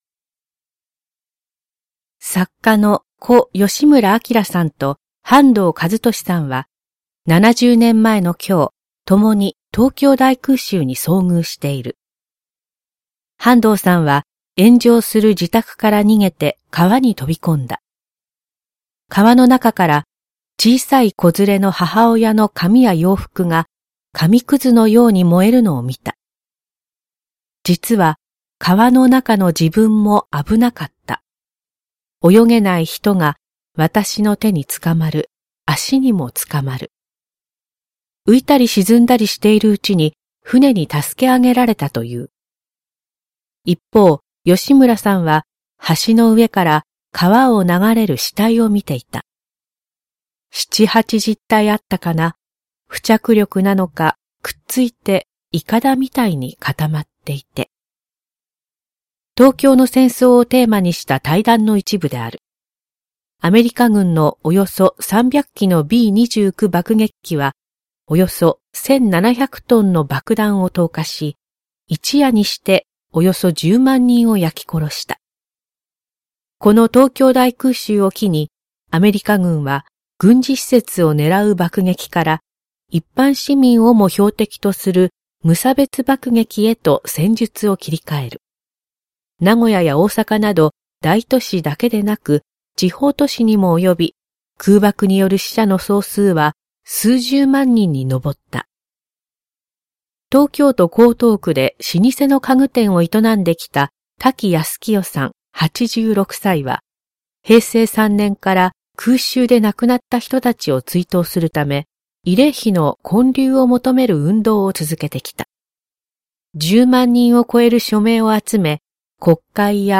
産経新聞1面のコラム「産経抄」を、局アナnetメンバーが毎日音読してお届けします。